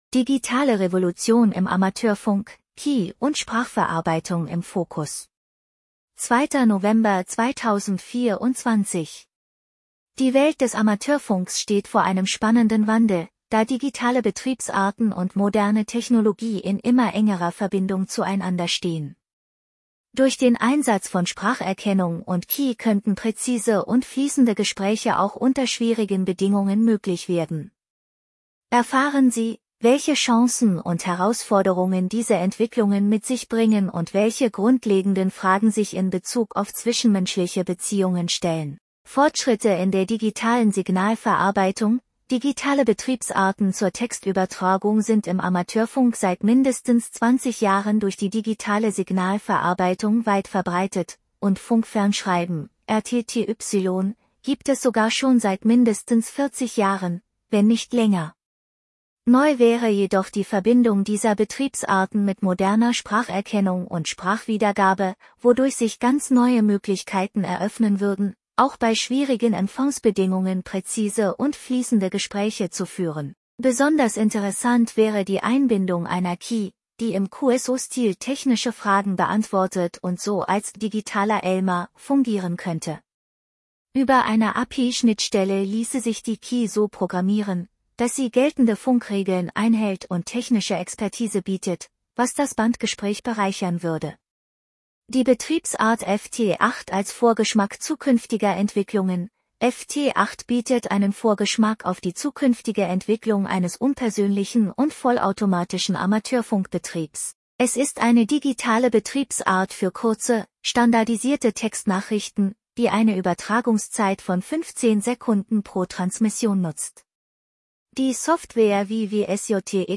Diesen Text (Stand 2.10.24) in drei Abschnitten vorlesen lassen: Erstellt mit ebenfalls künstlichen Stimmen von TTSMAKER